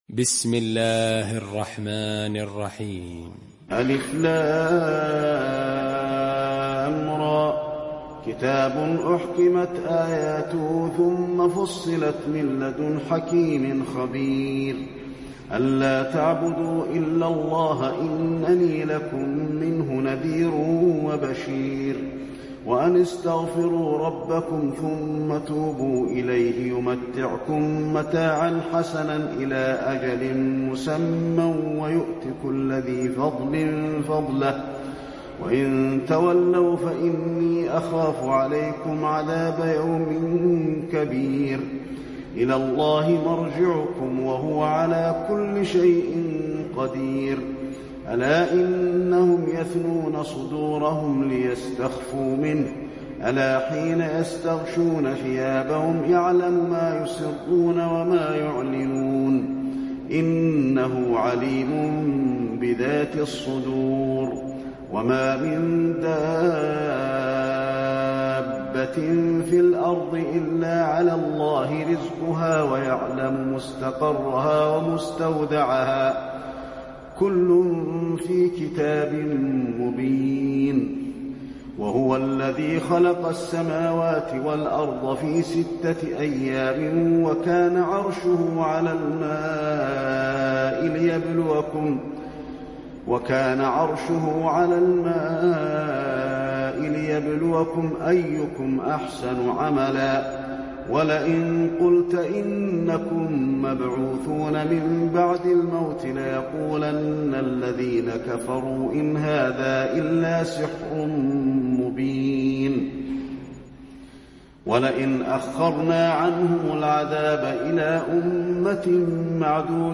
المكان: المسجد النبوي هود